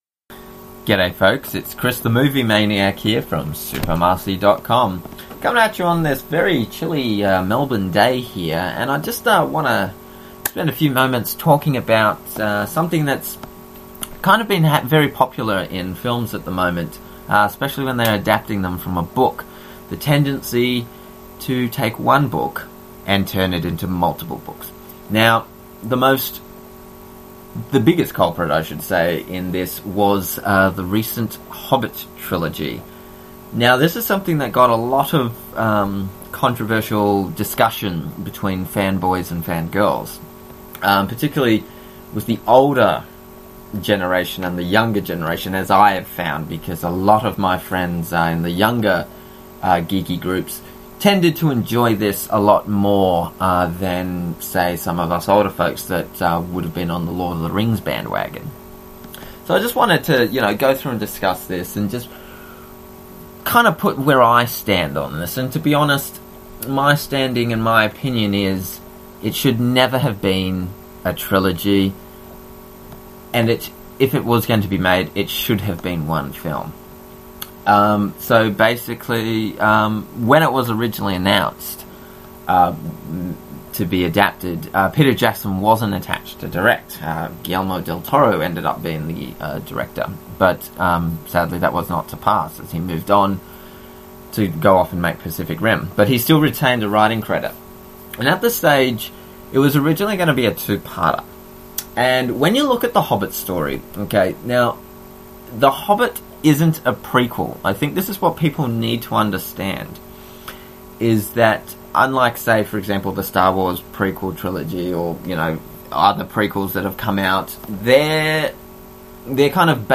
[Audio Discussion]
the-hobbit-rant.mp3